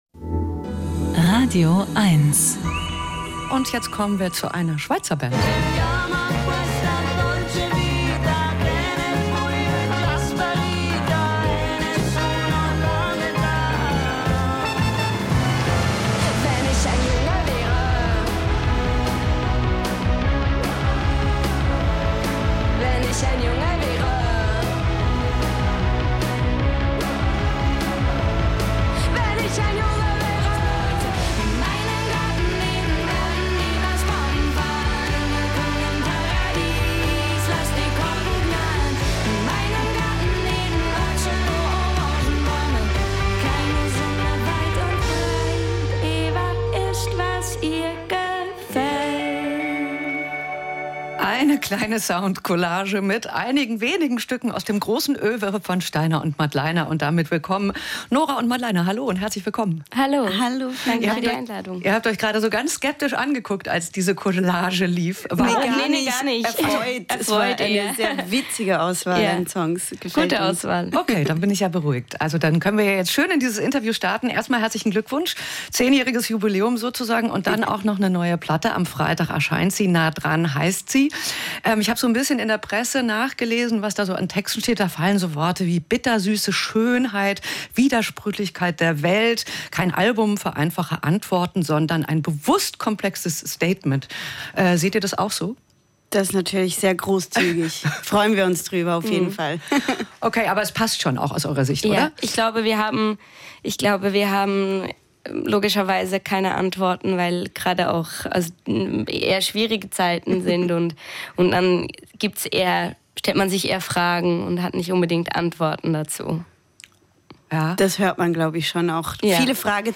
Mehr dazu von den beiden im radioeins-Studio.